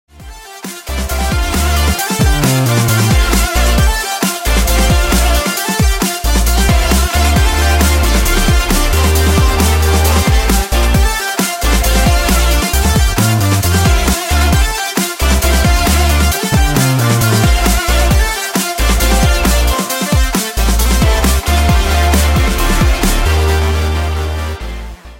Рингтоны Без Слов
Рингтоны Электроника